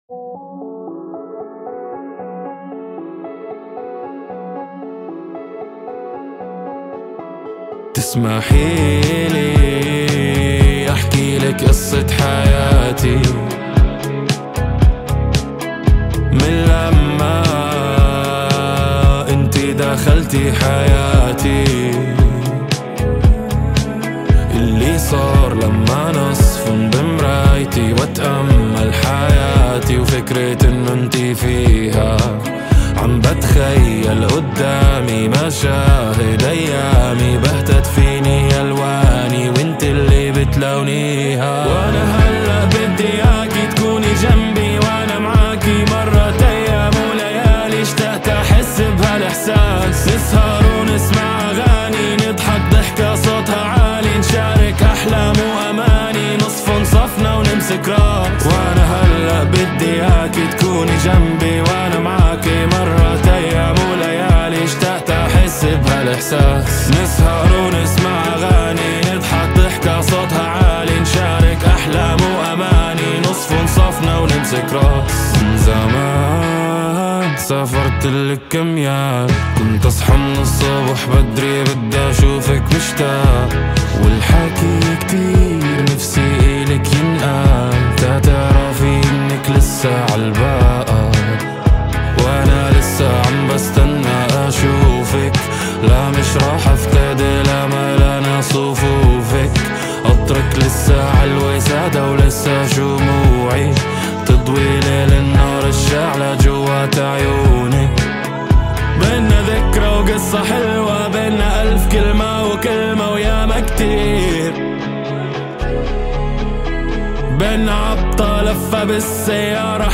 اشتهر بأغانيه التي تمزج بين الهيب هوب والبوب،